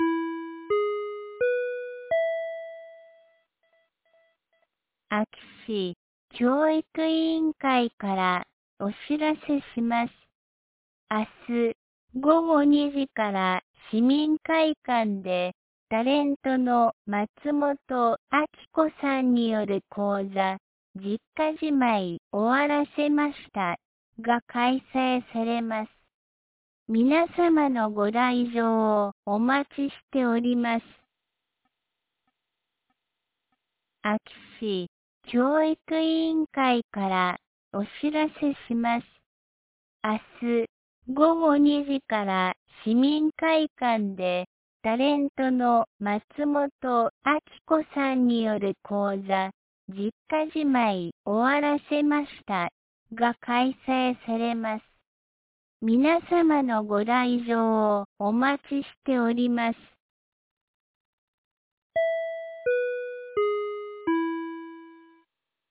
2025年09月05日 17時11分に、安芸市より全地区へ放送がありました。